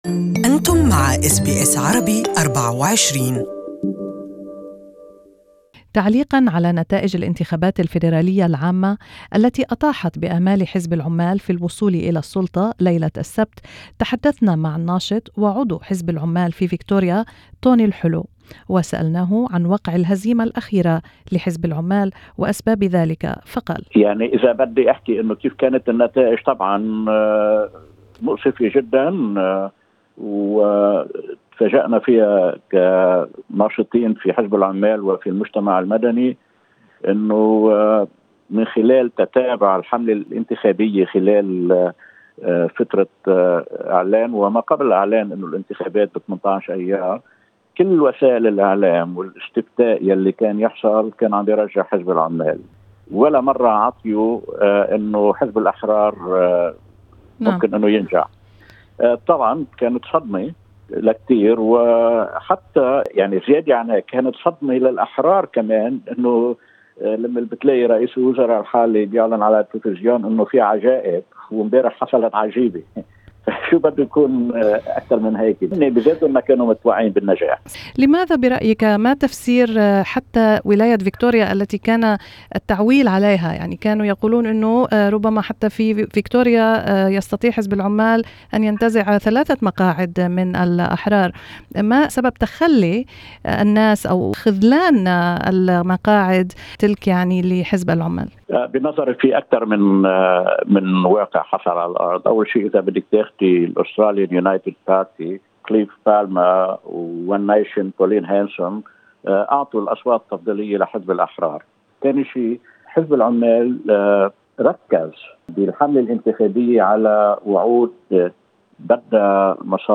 Listen to an interview in Arabic with Tony Helou a Labor member and former Morland Mayor.